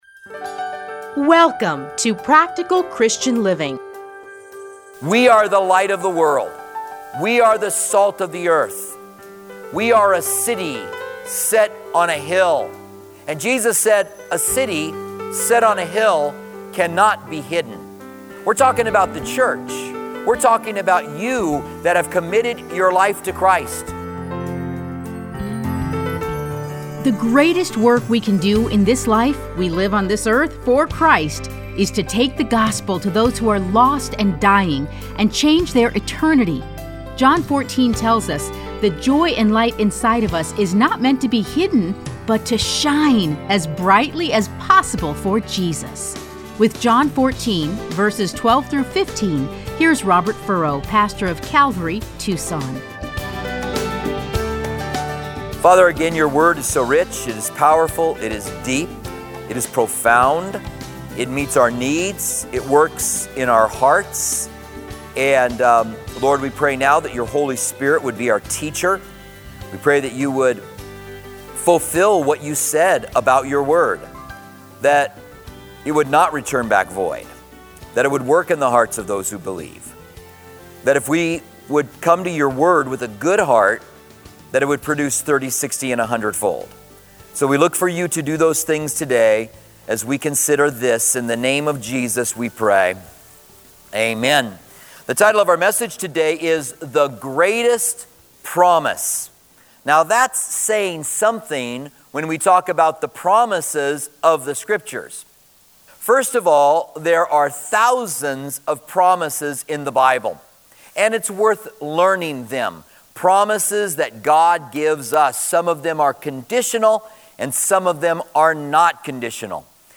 Listen to a teaching from John 14:12-15.